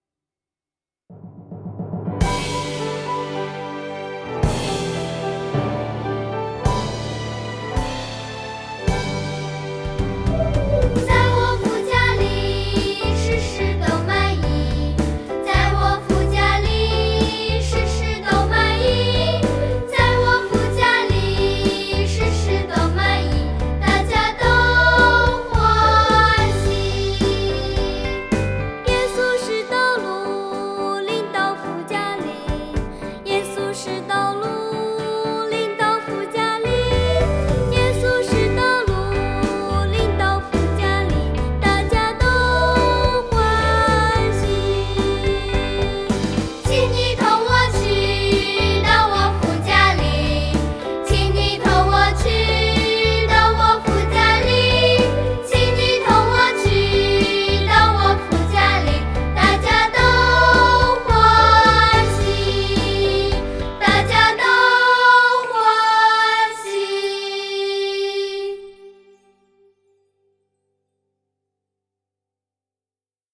儿童赞美诗 | 在我父家里